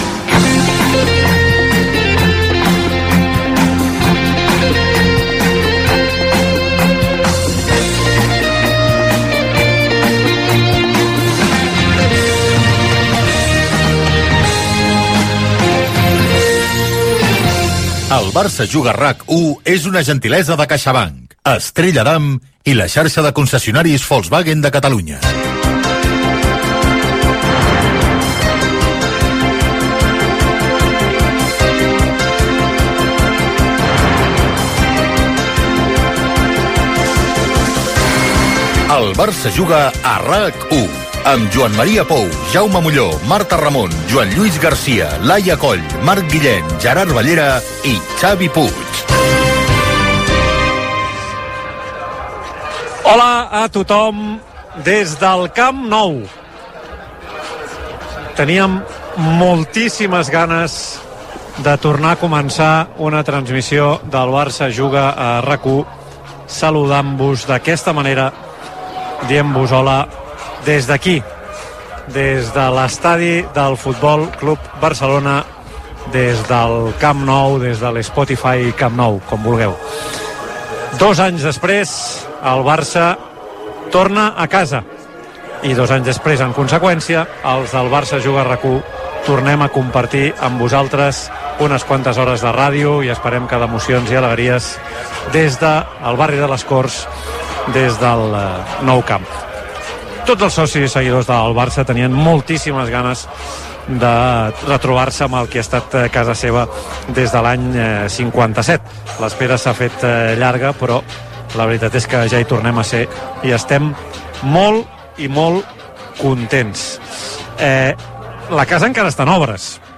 Transmissió del partit de la Lliga masculina de primera diviisó entre el Futbol Club Barcelona i l'Athletic Club de Bilbao en el dia de la reobertura parcial del Camp Nou després d'un parell d'anys d'obres de renovació i ampliació. Sintonia de la ràdio, careta del programa, comentari sobre el que significa retornar a jugar al Camp Nou i l'estat de les obres.
Descripció i entrevista a l'exterior del camp.